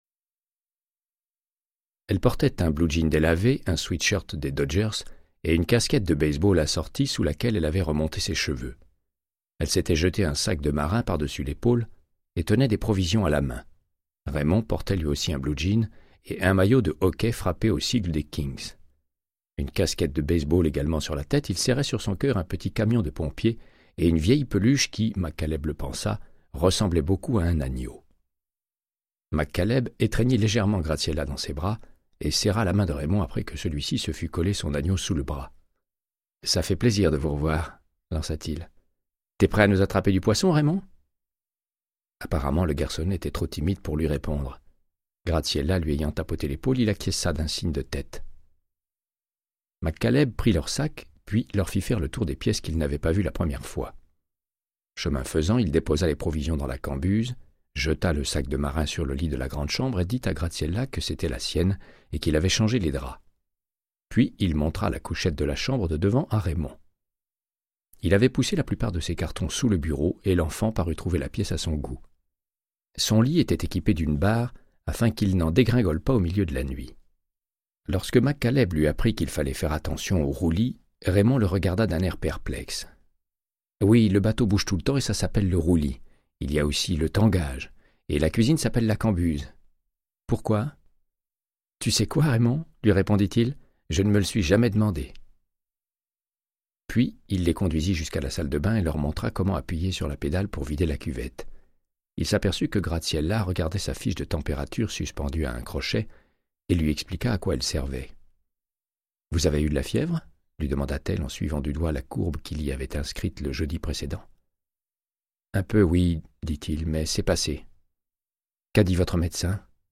Audiobook = Créance de sang, de Michael Connellly - 87